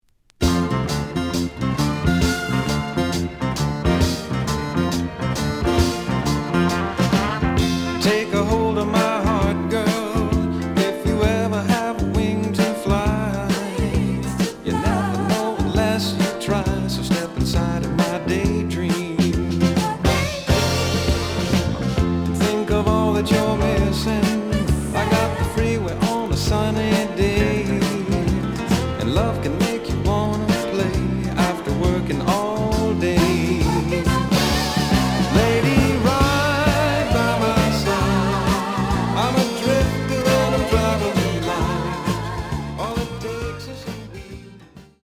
The audio sample is recorded from the actual item.
●Genre: Soul, 70's Soul
A side plays good.